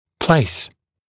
[pleɪs][плэйс]устанавливать; место